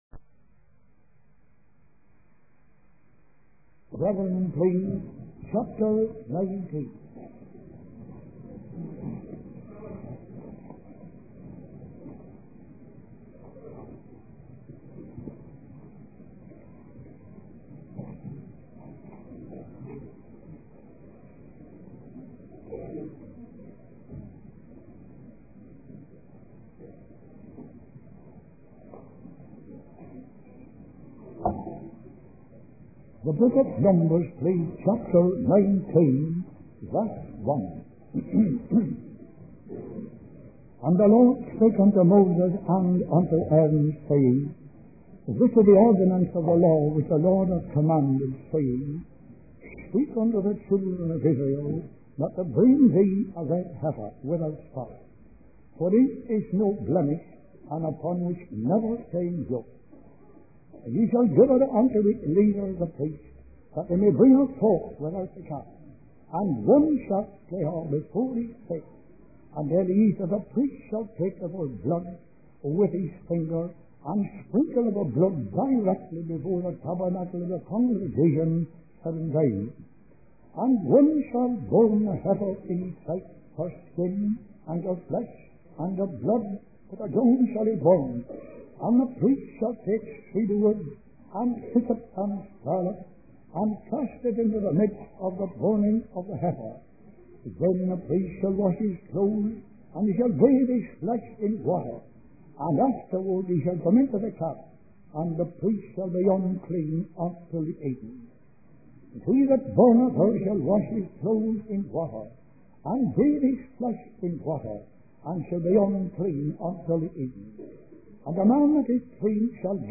In this sermon, the preacher emphasizes the importance of following God's word without questioning or backtalk.